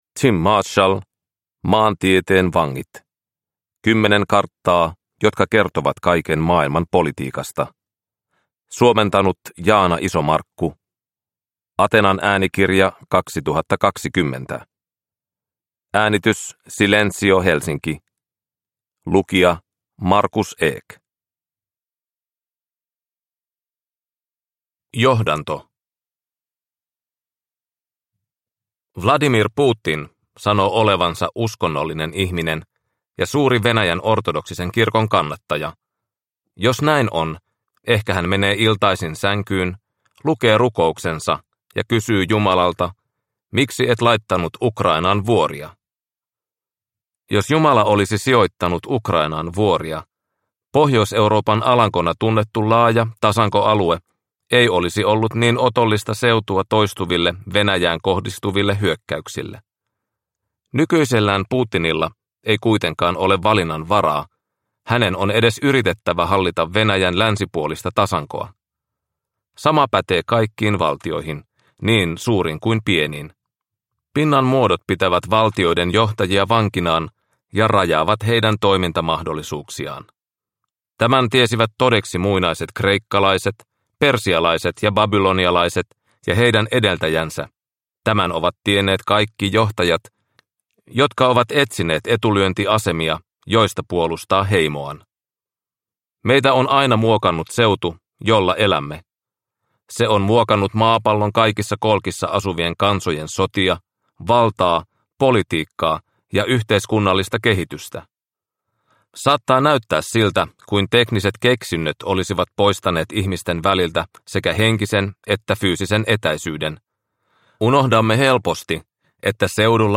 Maantieteen vangit – Ljudbok